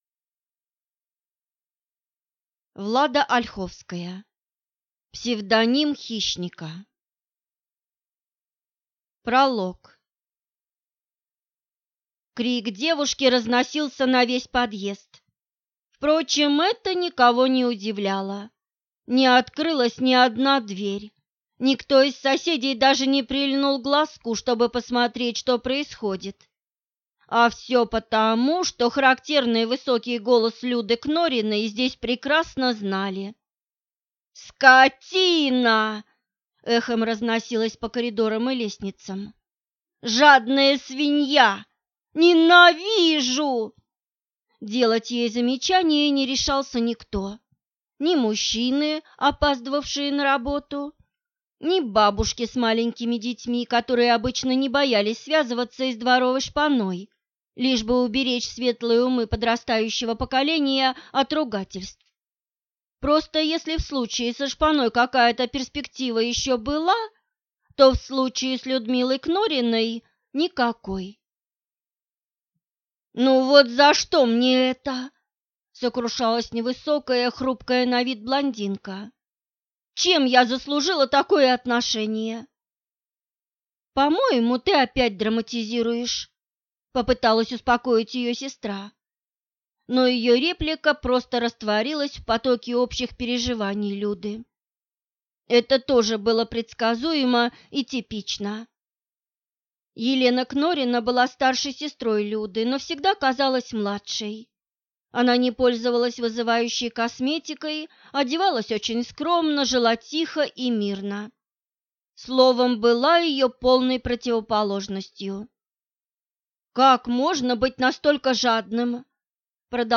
Аудиокнига Псевдоним хищника | Библиотека аудиокниг
Прослушать и бесплатно скачать фрагмент аудиокниги